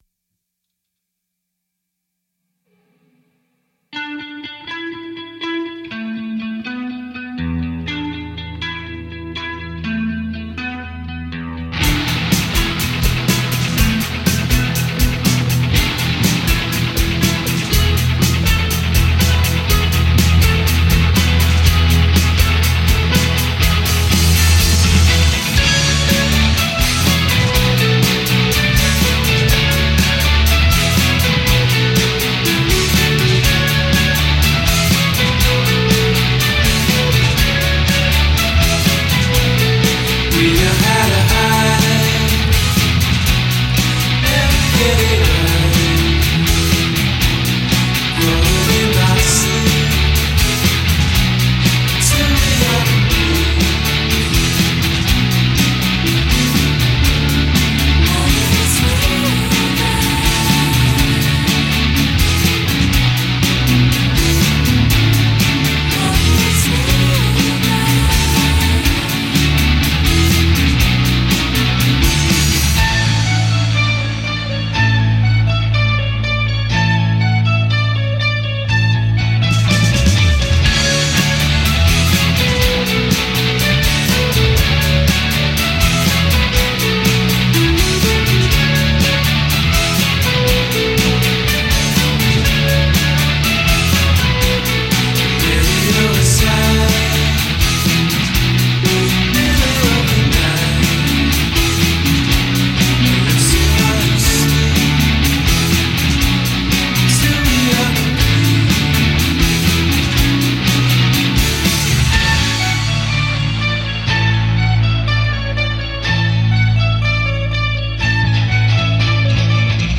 pezzoni alla Breeders
chitarra
batteria